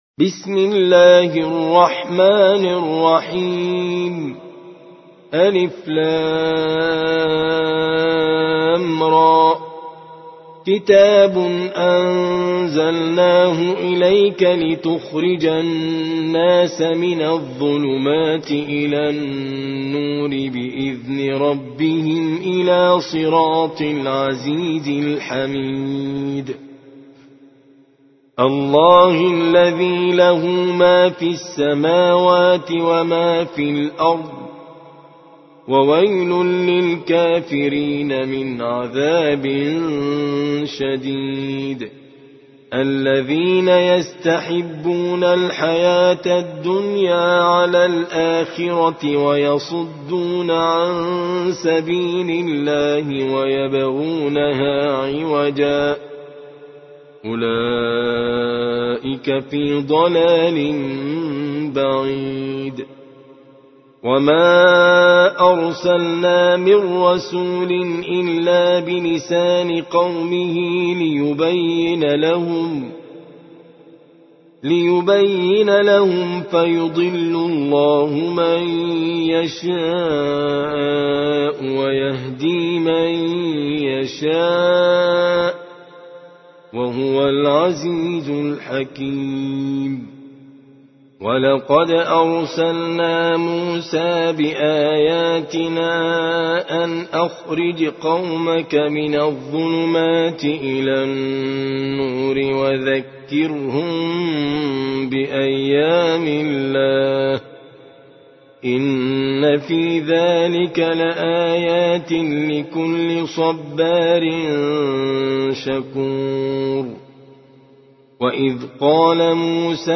14. سورة إبراهيم / القارئ